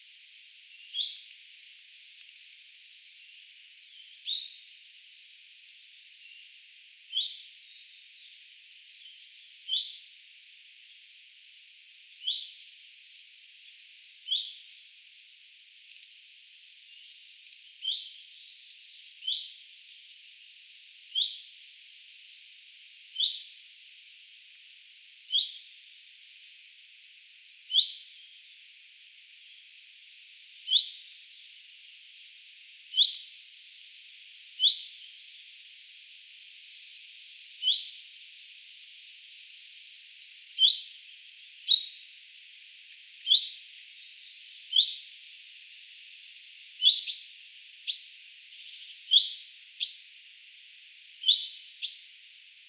vähän eri tavalla huomioääntelevä peippo
Hiukan on oppinut samalla paikalla pesivältä
erikoisesti_aanteleva_peippo_vahan_eri_tavalla.mp3